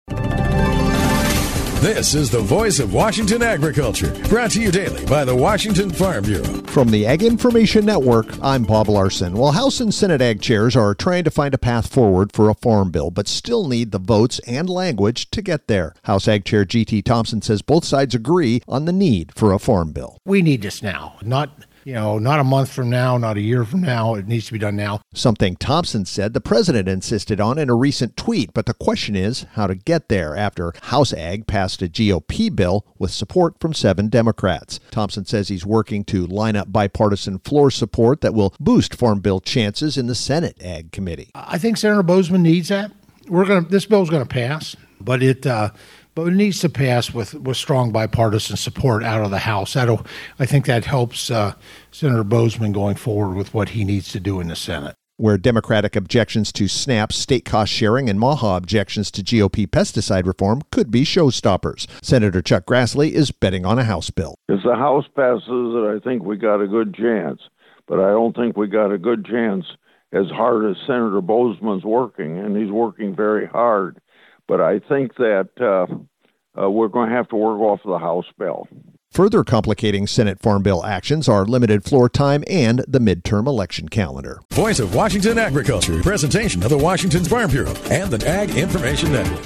Monday Mar 30th, 2026 53 Views Washington State Farm Bureau Report